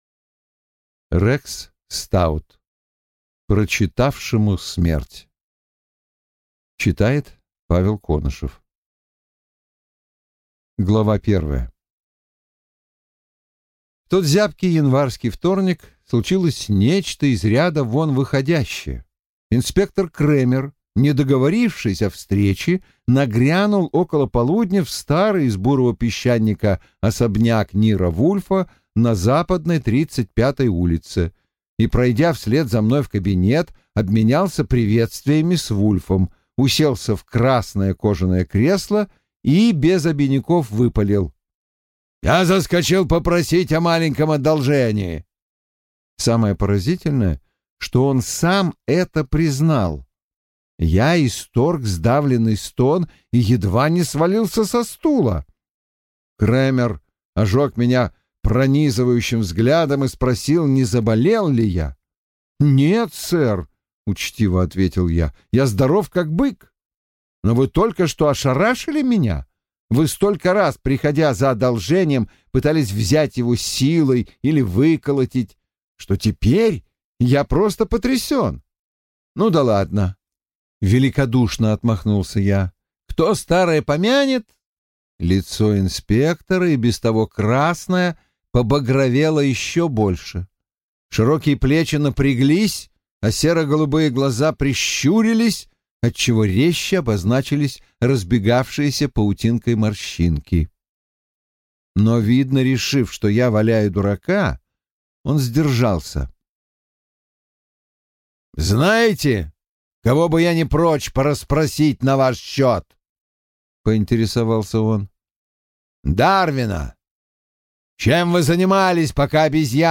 Аудиокнига Прочитавшему – смерть | Библиотека аудиокниг